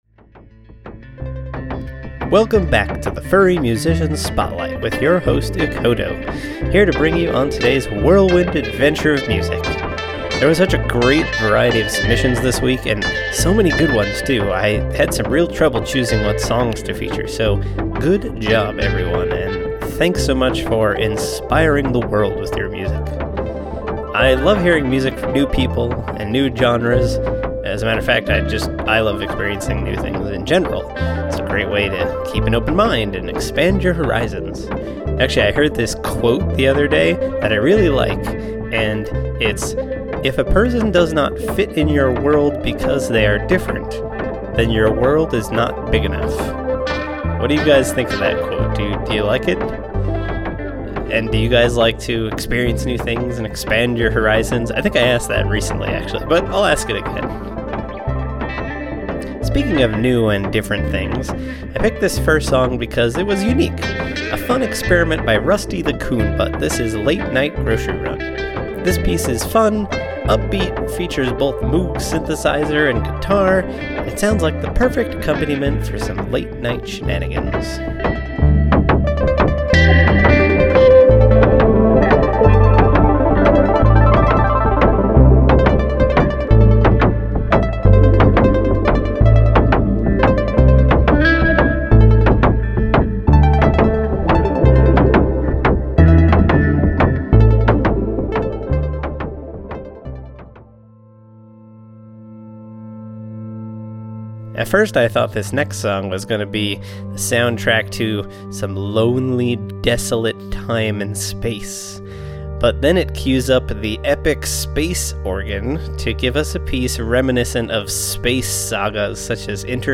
Thanks for tossing my funky guitar and moog track on here!!!